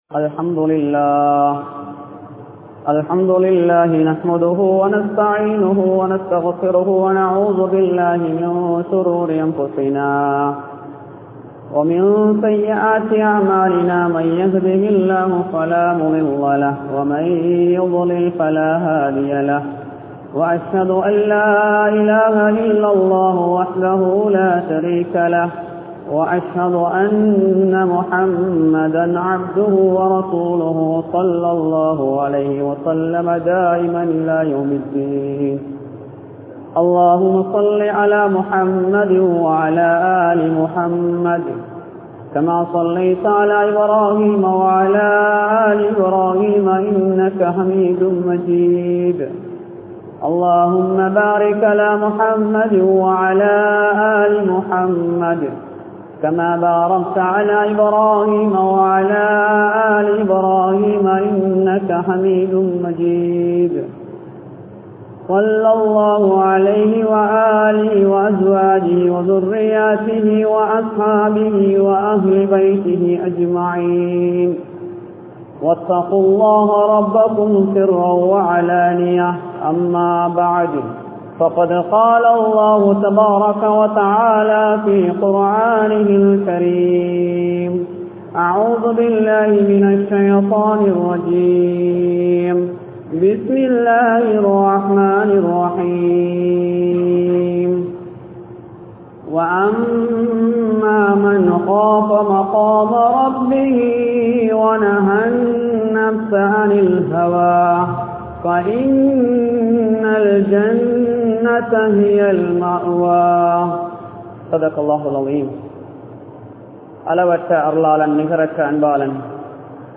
Vaalkaiyai Alikkum Paavangal (வாழ்க்கையை அழிக்கும் பாவங்கள்) | Audio Bayans | All Ceylon Muslim Youth Community | Addalaichenai